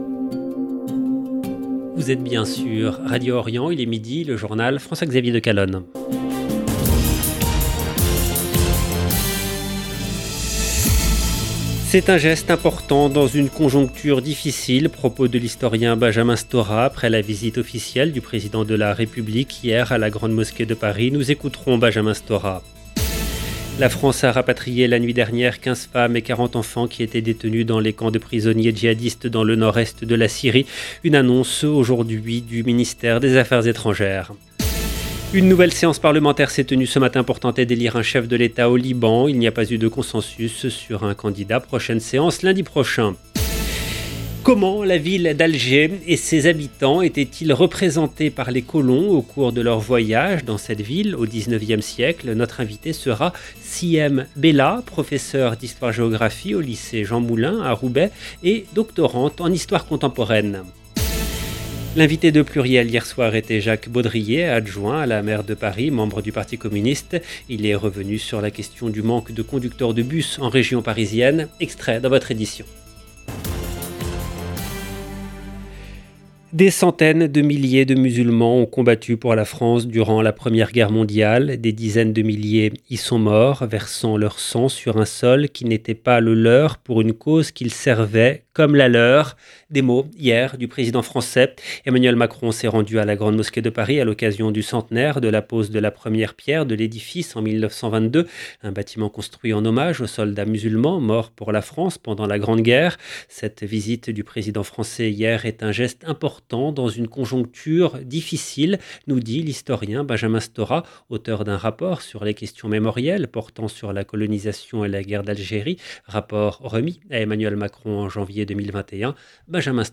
Journal présenté